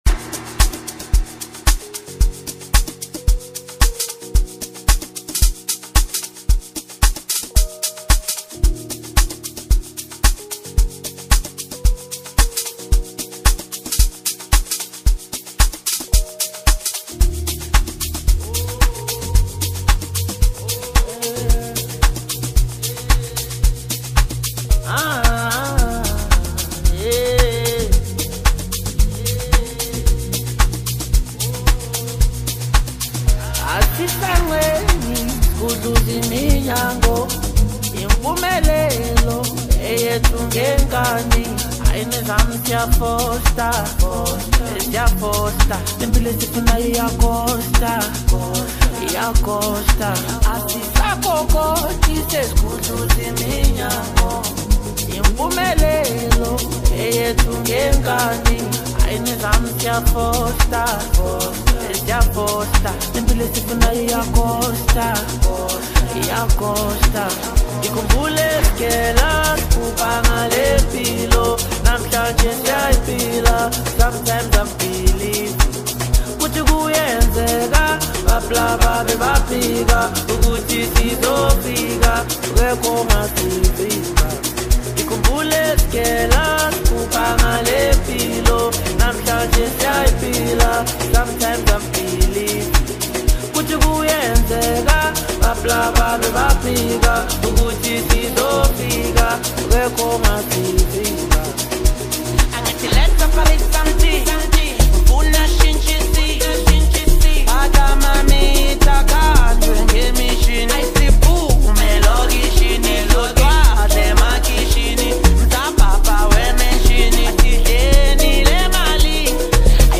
Amapiano Sound